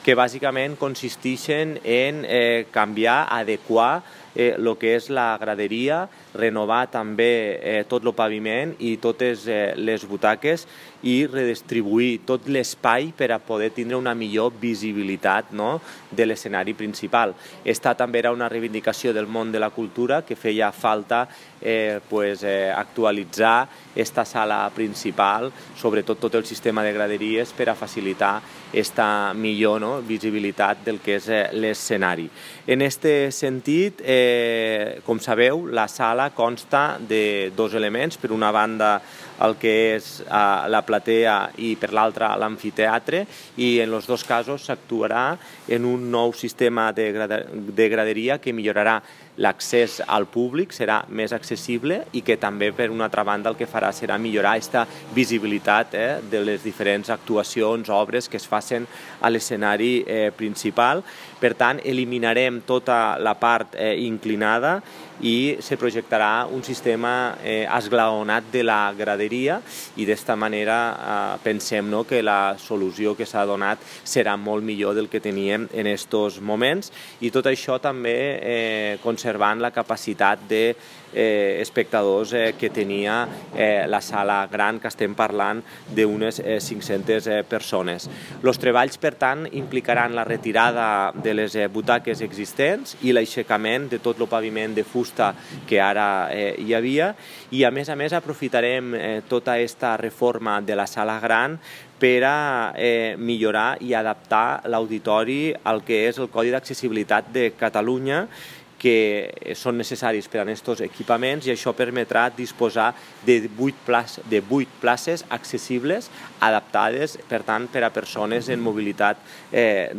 Jordi Jordan, Alcalde de Tortosa